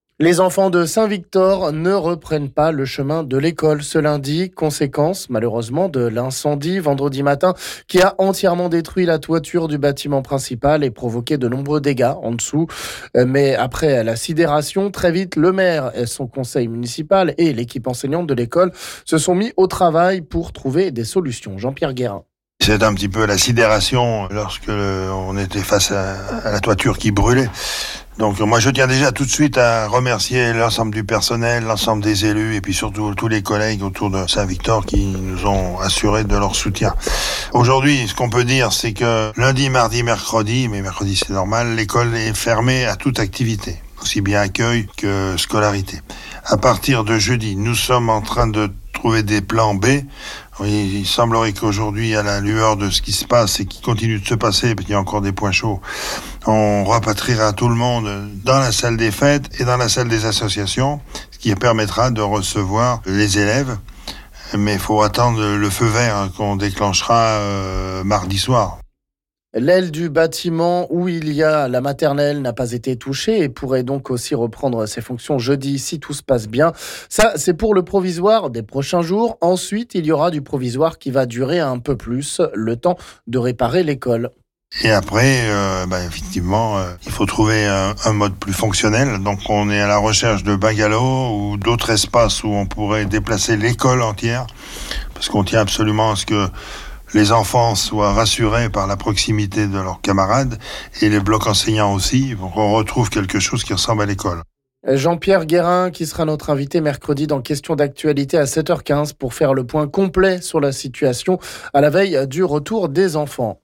On en parle ici avec le maire Jean-Pierre Guérin...